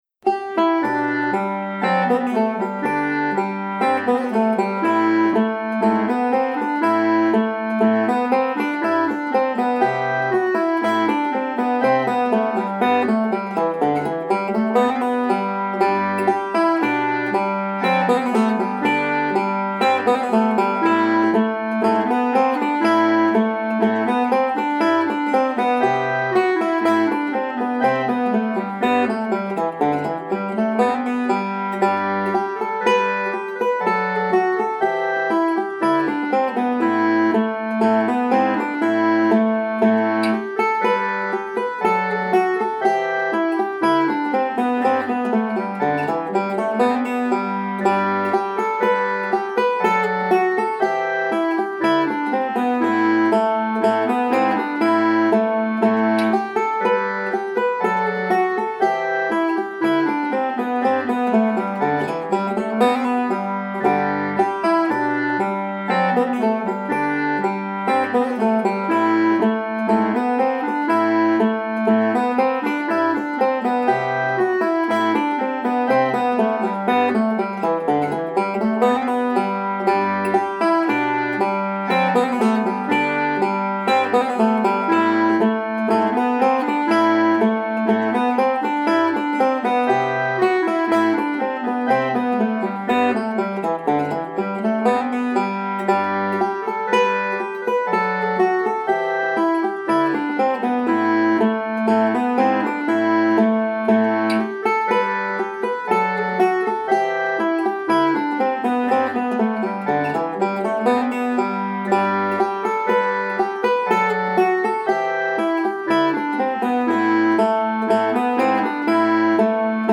5-STRING BANJO
SOLO
• Welch and Irish, Standard notation and Tab